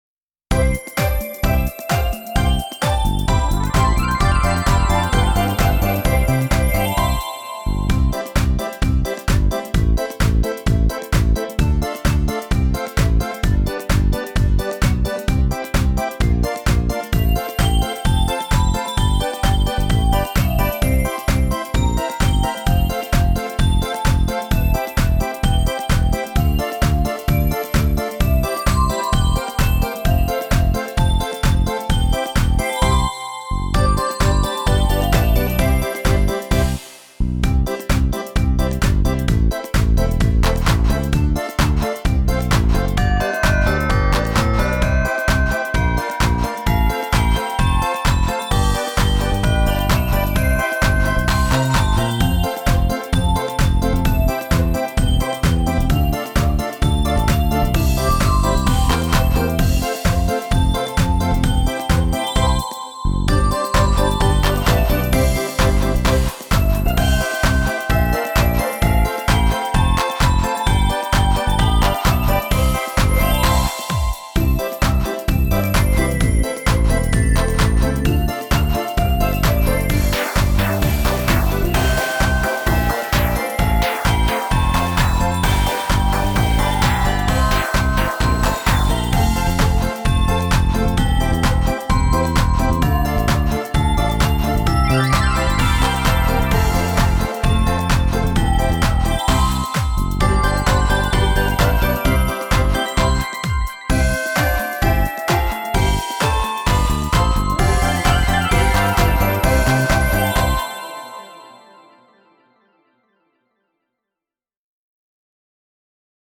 2曲とも、アップテンポで耳馴染みが良く、口ずさみたくなる楽しい曲となっています。
カラオケ